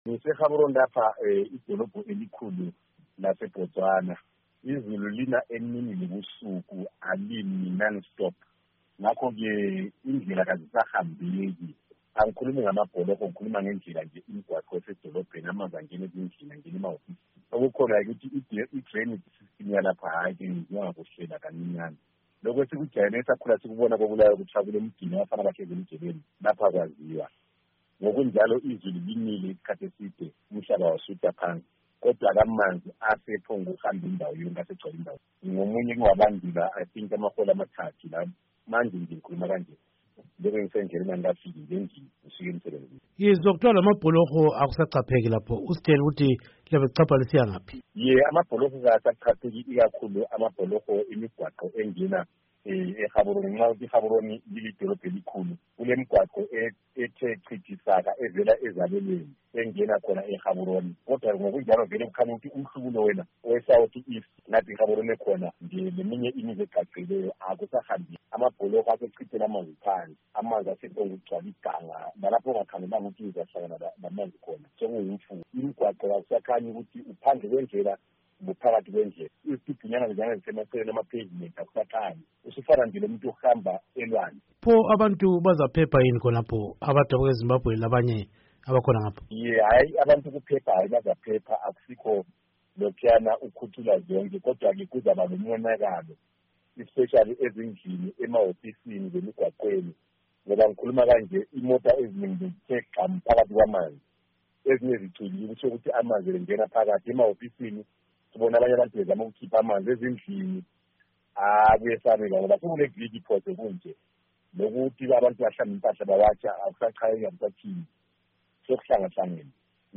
Ingxoxo Esiyenze loMnu